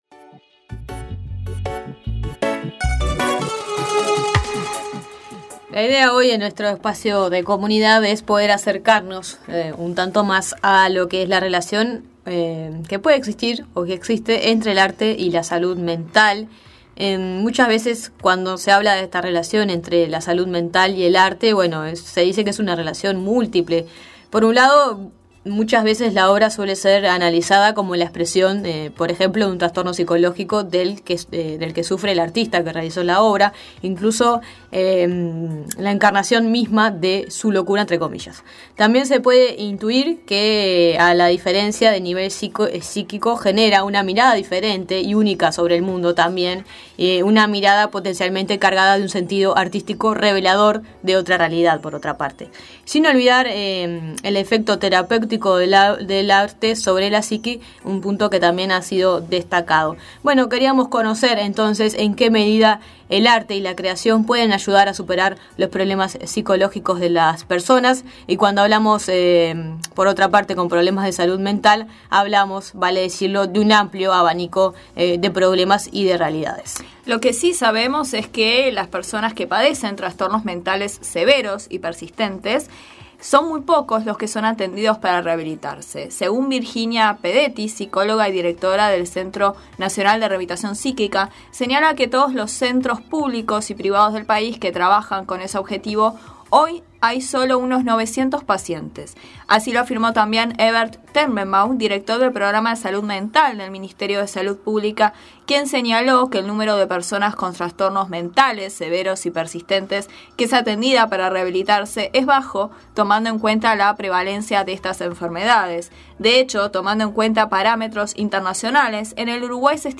La Nueva Mañana conversó